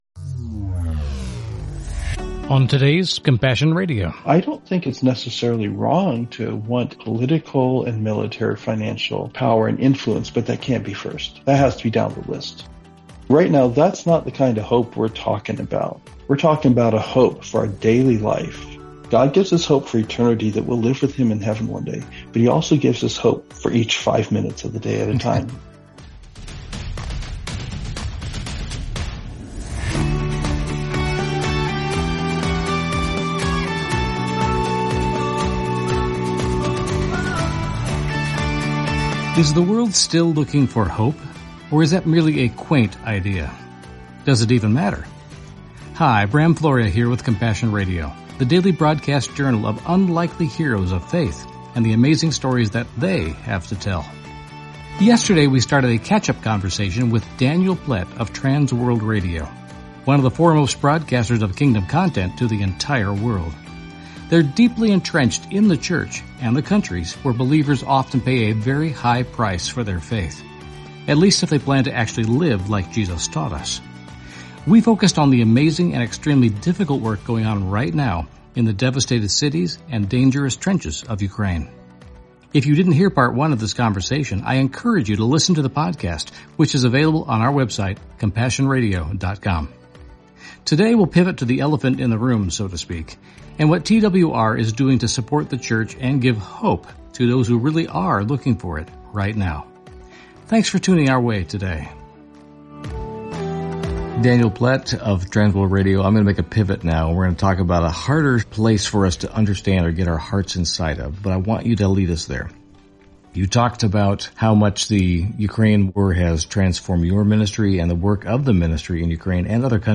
Genre: Christian News Teaching and Talk.